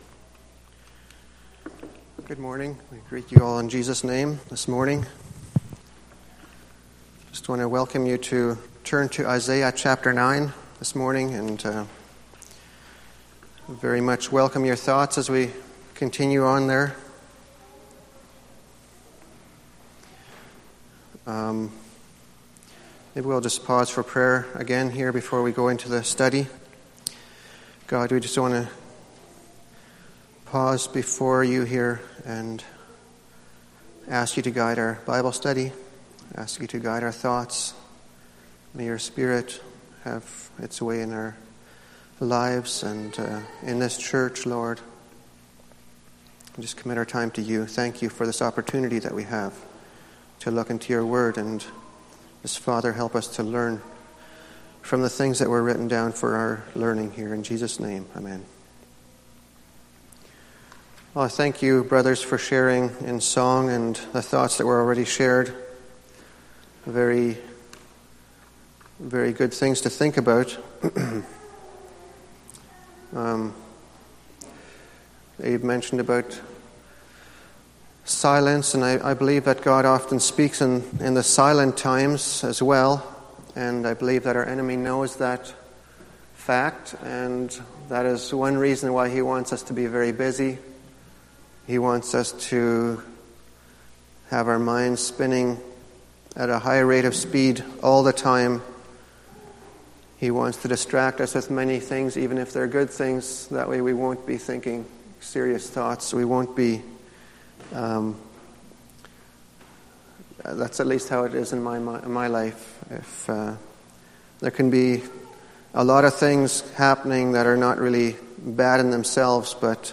Sunday Morning Bible Study Service Type: Sunday Morning %todo_render% « My Responsibility in Reconciliation The Main Point